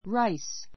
ráis ラ イ ス